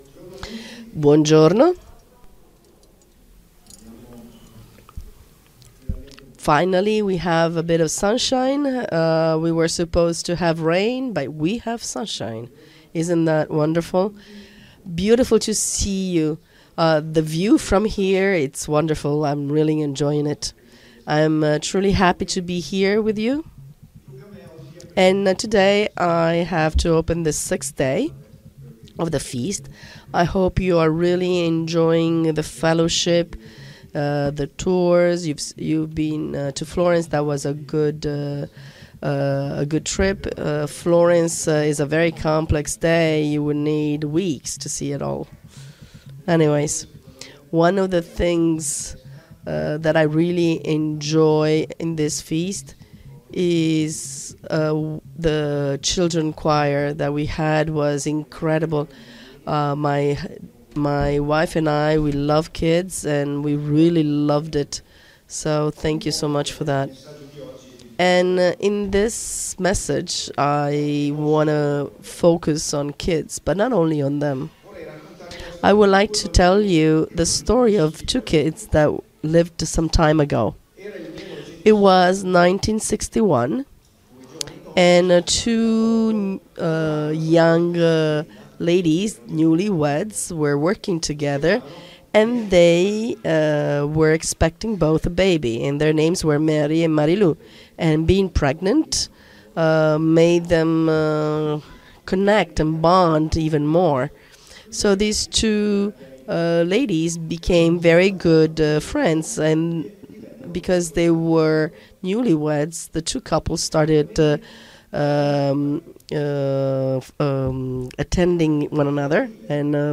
FoT 2024 Marina di Grosseto (Italy): 6th day